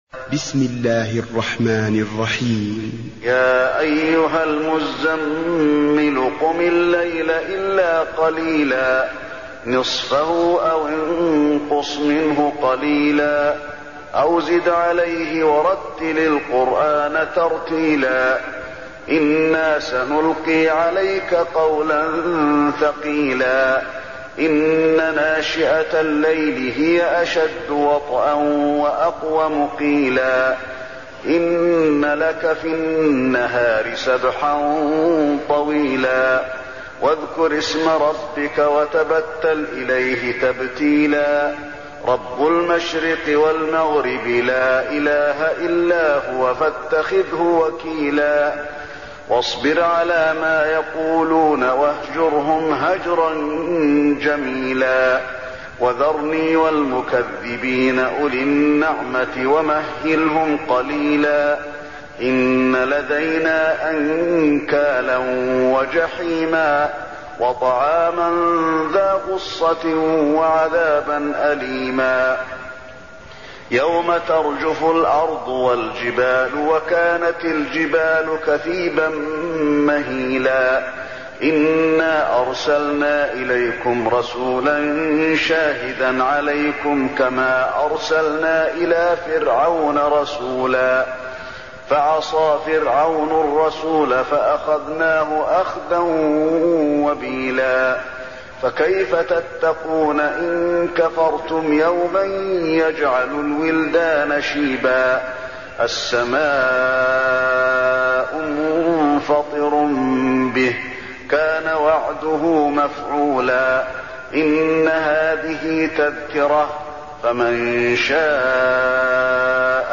المكان: المسجد النبوي المزمل